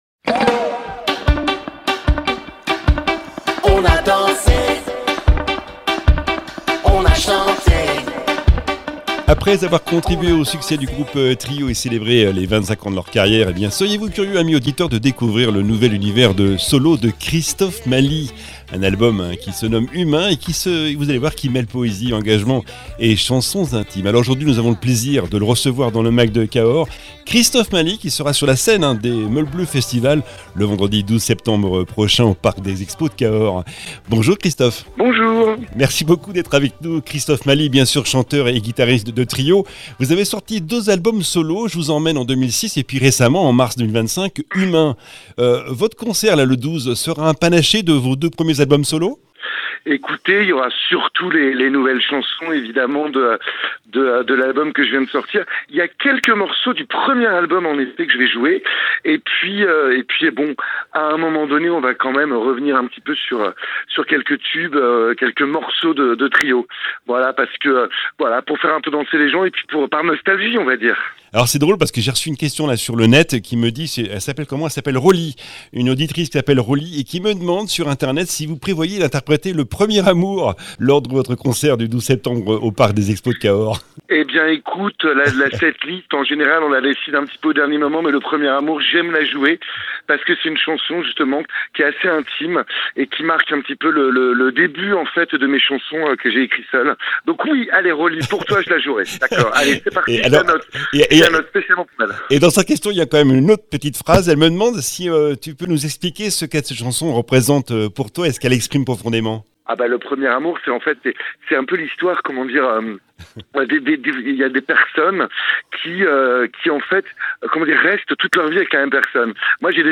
Invité(s) : Christophe MALI, auteur, compositeur et interprète